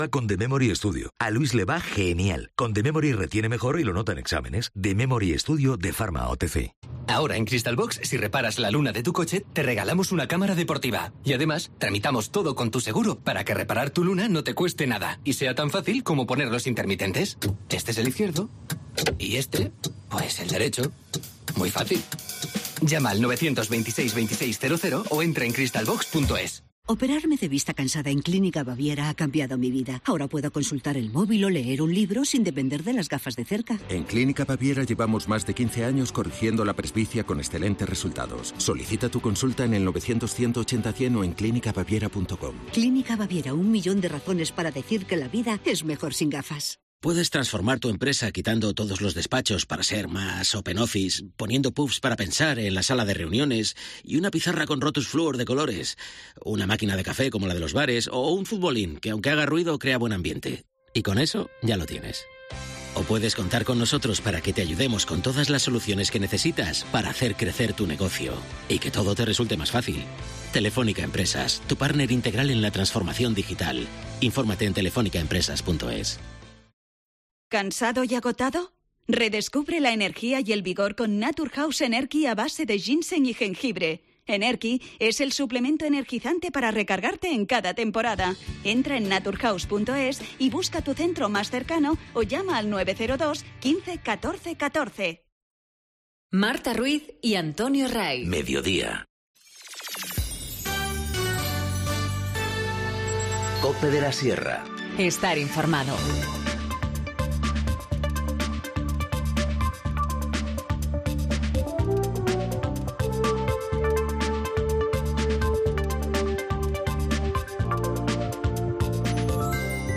Informativo Mediodía 13 mayo 14:20h